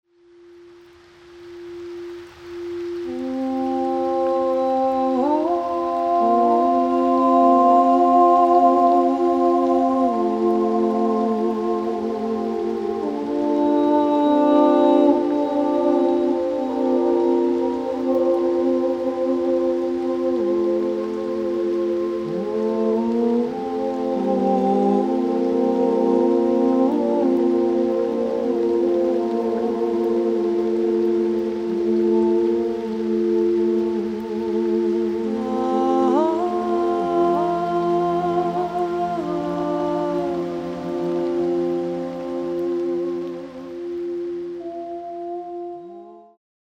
Two 30 minute Meditations in the Key of F.
Good for meditation, insomnia, relaxation.